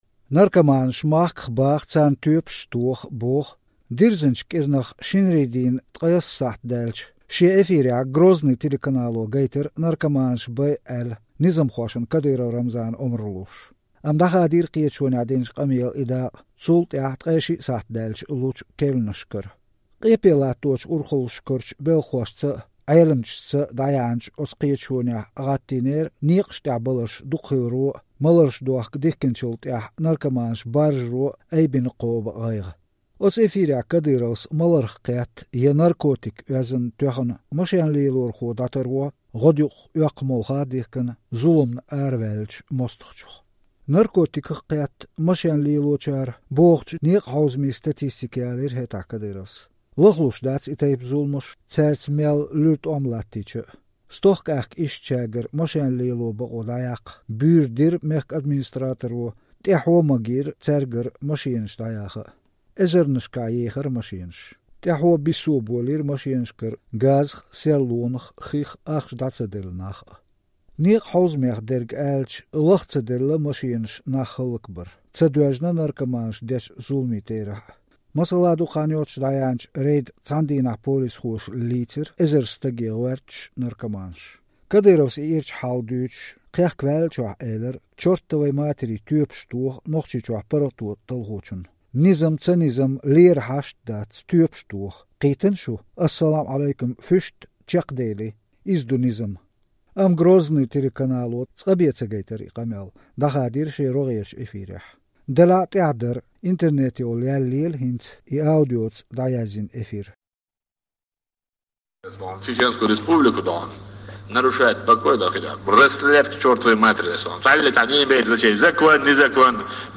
Дирзинчу кIиранах, шинарийдийнан 19 сахьт даьлча, шен эфирехь «Грозный» телеканало гайтинера наркоманаш байъа, аьлла, низамхошна Кадыров Рамзана омра луш.